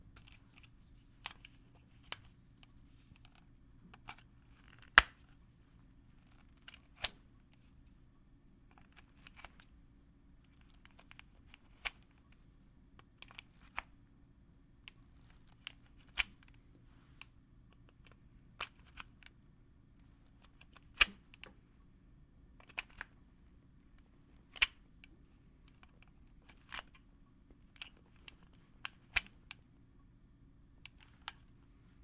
环境 " 电力线 oct05at48k
记录于2005年10月10日黄昏时分。